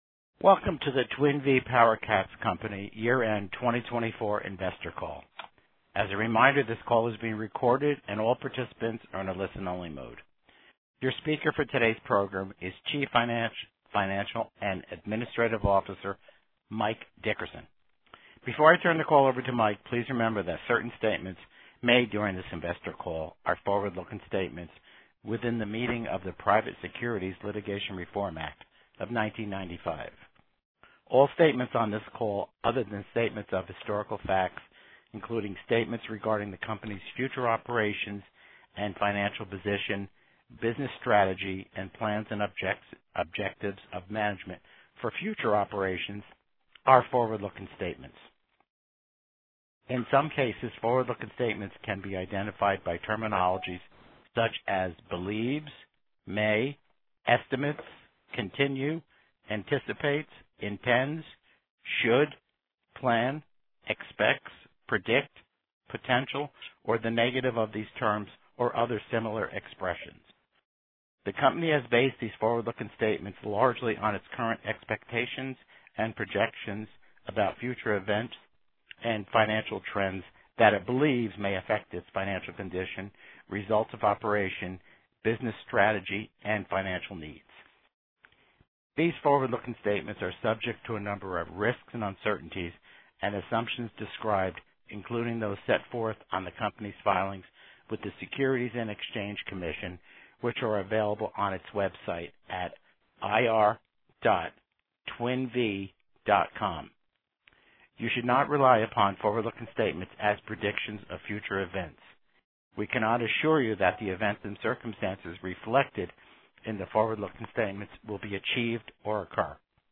Twin Vee PowerCats Co. Fourth Quarter 2024 Financial Results Conference Call